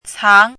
chinese-voice - 汉字语音库
cang2.mp3